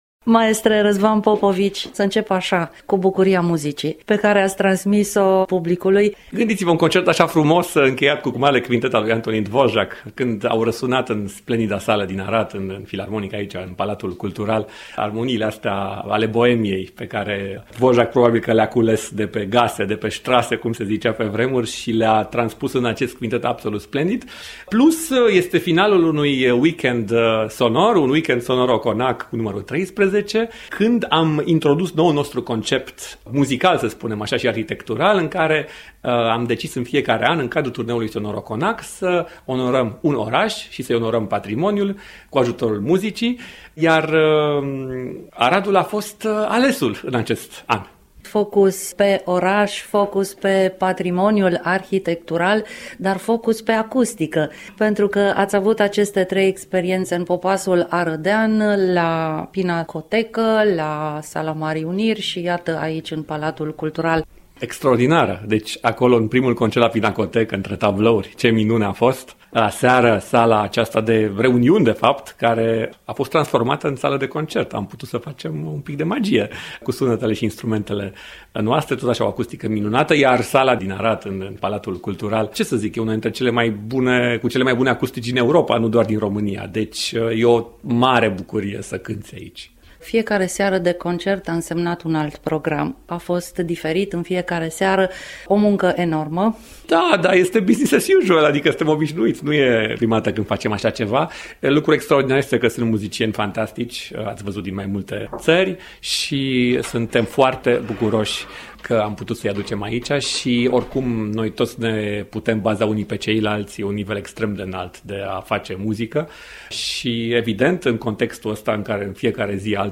interviu realizat la finalul turneului, 29 iunie 2025, Arad